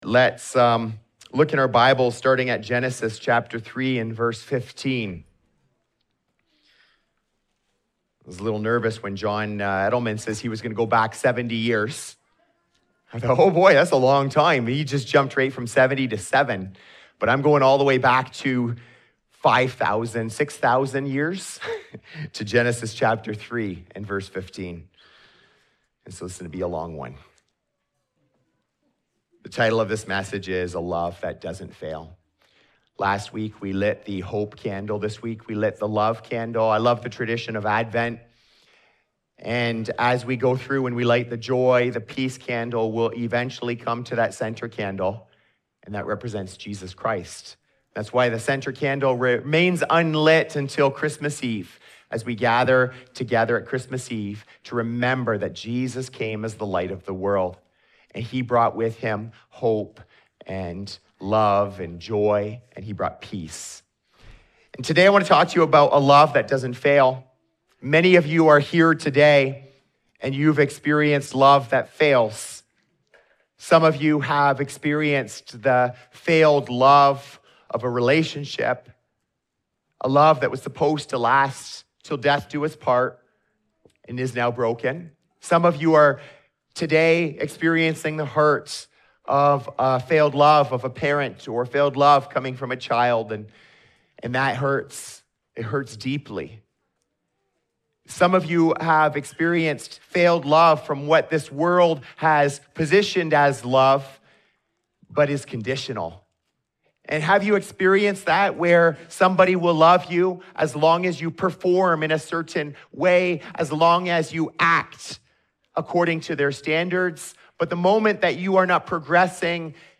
In this Advent message, we talk about a love that isn’t fragile or temporary—a love that was promised from the very beginning, arrived in the person of Jesus, and is still meant to be received and lived out today.